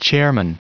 Prononciation du mot chairman en anglais (fichier audio)
Prononciation du mot : chairman